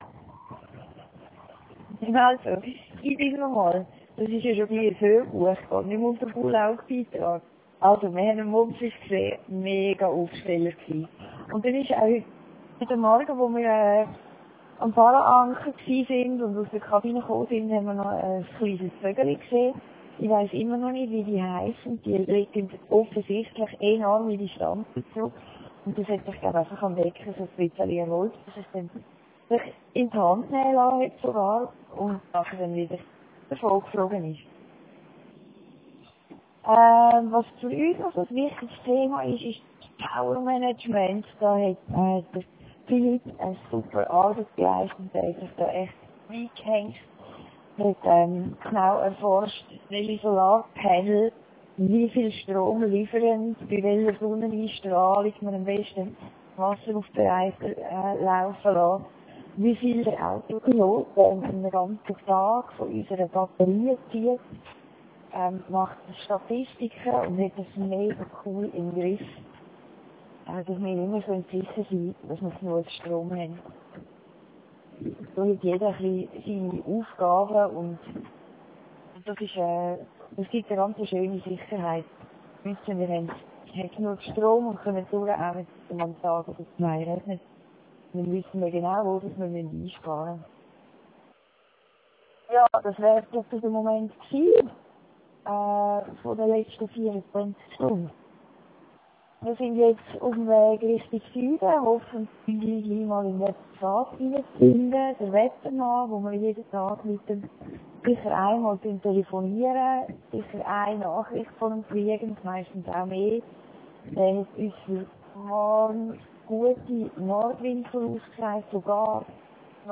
...und der zweite Teil der O-Ton Nachricht